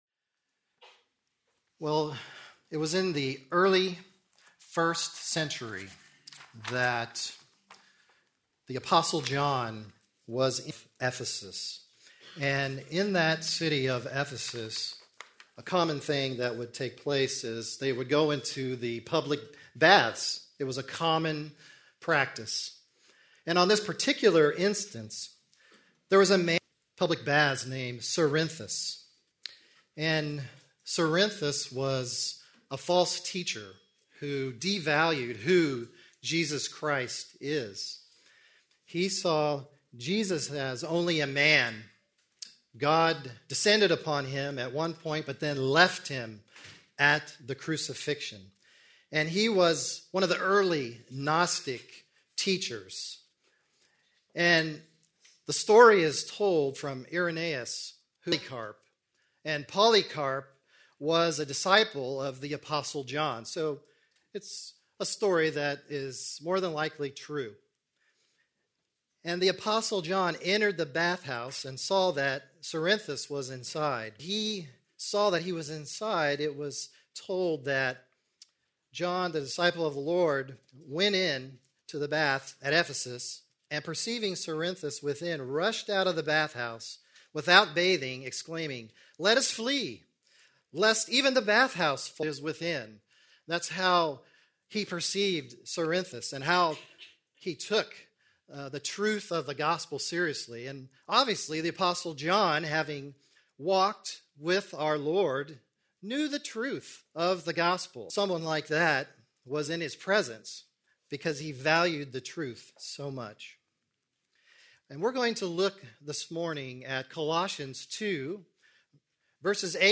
Please note, due to technical difficulties, this recording skips brief portions of audio.